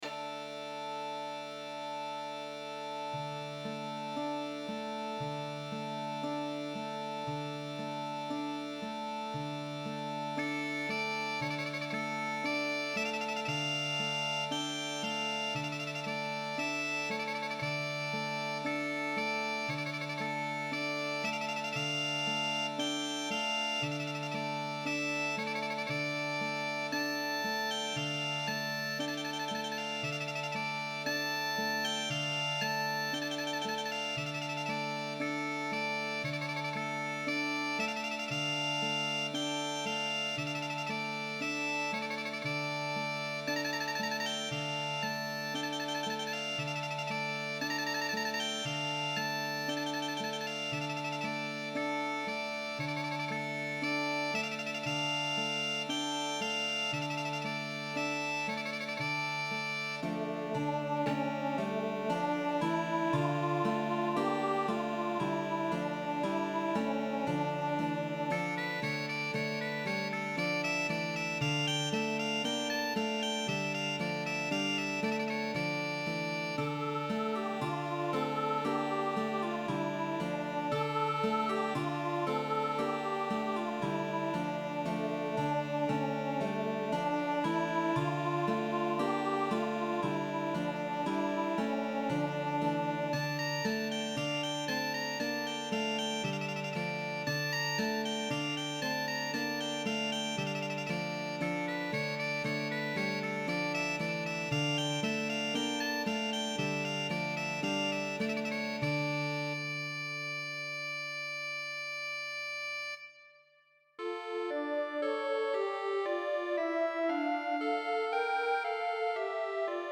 ORCHESTRA DIDATTICA
Noel tradizionale